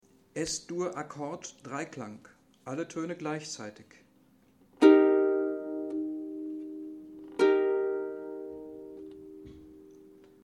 Ein Akkord ist grundsätzlich das gleichzeitige Erklingen mehrerer Töne in einer einzelnen Stimme ( beispielsweise auf einer Harfe, einer Violine, einer Klavier(hand) ).
Hörbeispiel Akkord